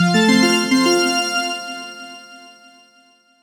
levelup success victory winning sound effect free sound royalty free Gaming